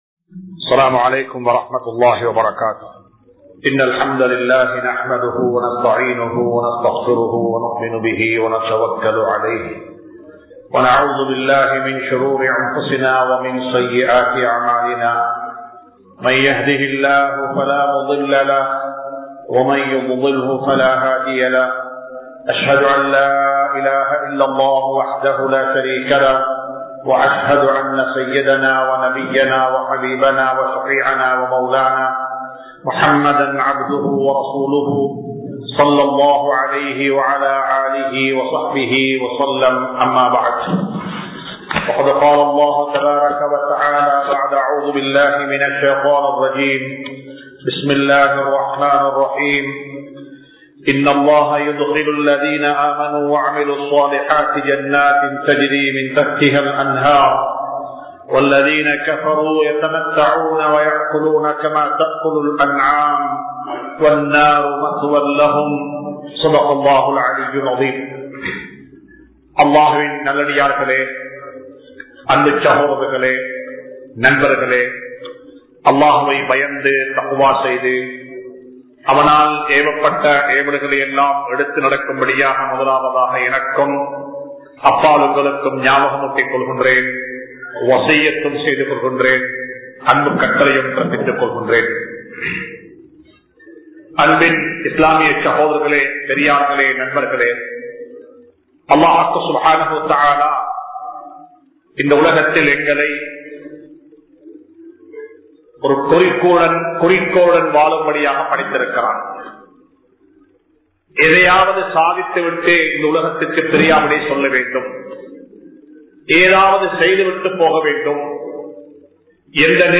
Manitha Neayam Ullavaraha Vaalungal (மனிதநேயம் உள்ளவராக வாழுங்கள்) | Audio Bayans | All Ceylon Muslim Youth Community | Addalaichenai
Dehiwela, Muhideen (Markaz) Jumua Masjith